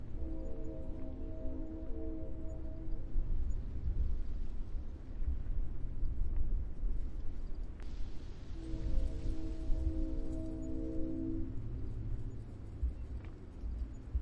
基础音效 " S025 空气号角深沉的声音单声道
描述：从呼吸喇叭吹起，深沉的声音
Tag: 人群 长期爆炸 Airhorn